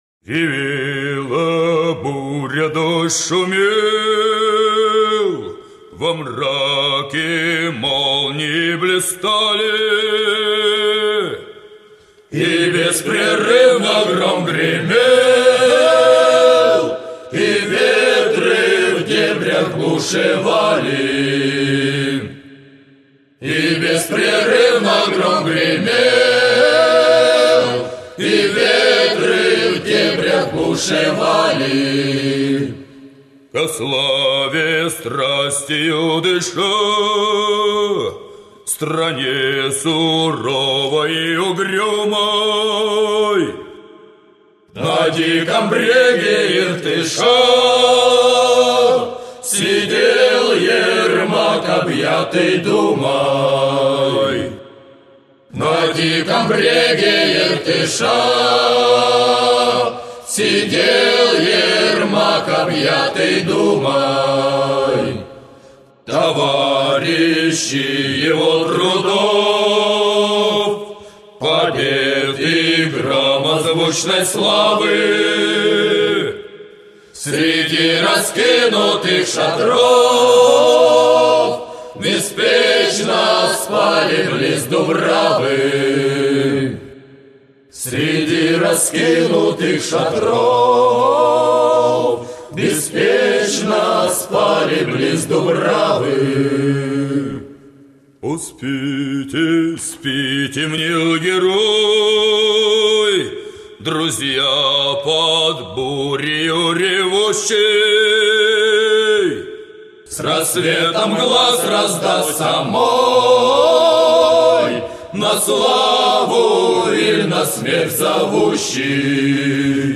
«Смерть_Ермака»_в_исполнении_Кубанского_казачьего_хора.ogg